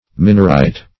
Minorite \Mi"nor*ite\, n.